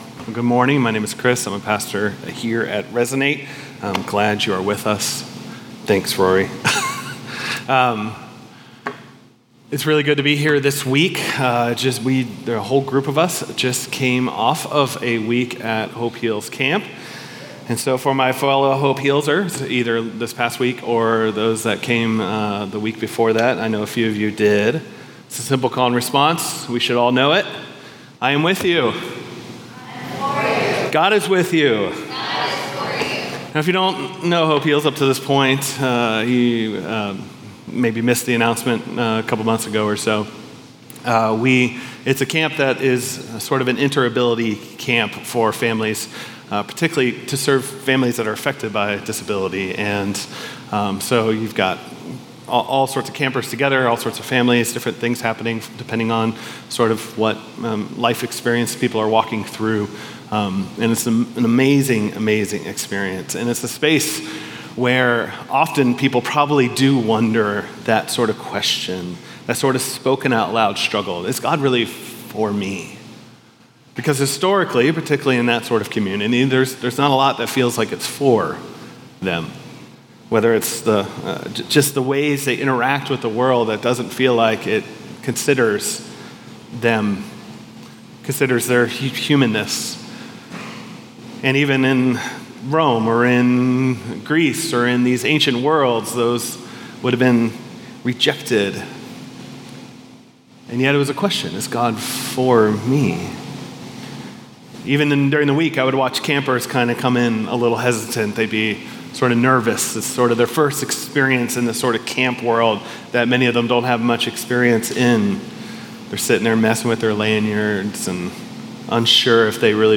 All Sermons The God Who…